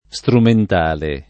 vai all'elenco alfabetico delle voci ingrandisci il carattere 100% rimpicciolisci il carattere stampa invia tramite posta elettronica codividi su Facebook strumentale [ S trument # le ] (lett. istrumentale [ i S trument # le ]) agg.